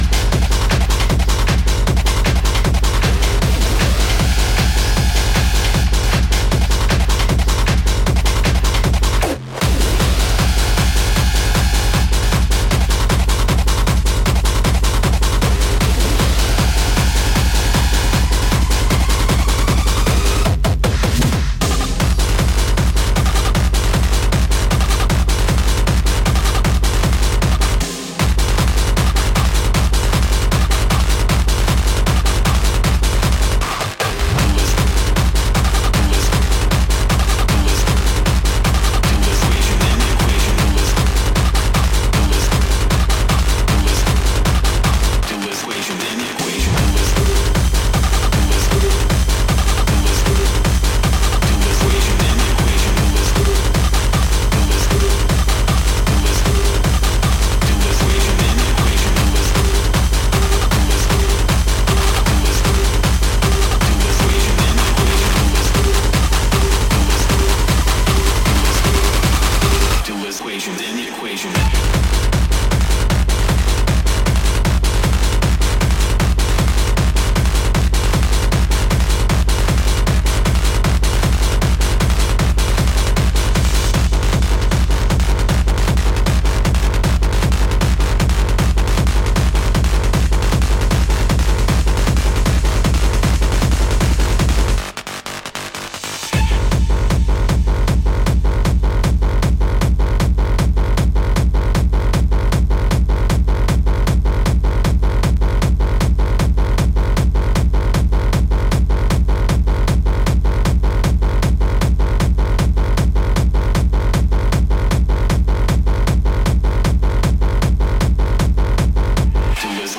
Techno, Hardcore